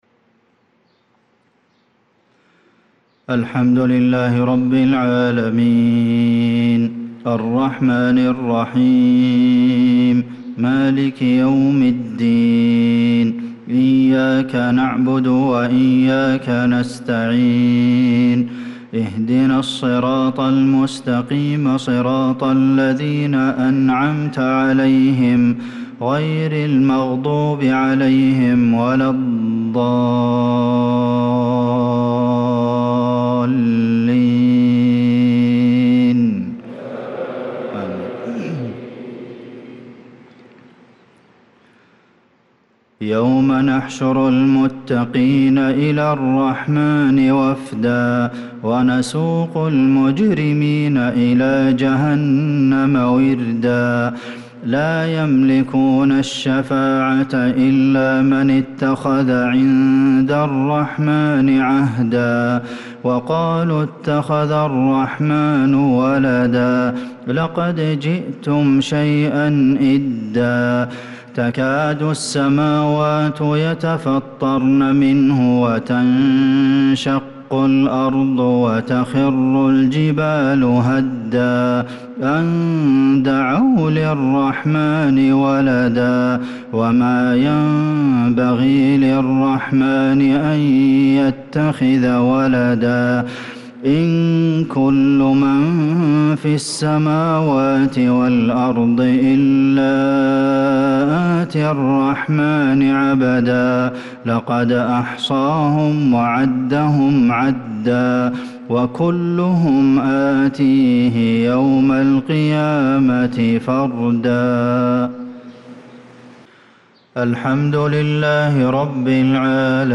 صلاة المغرب للقارئ عبدالمحسن القاسم 13 ذو الحجة 1445 هـ
تِلَاوَات الْحَرَمَيْن .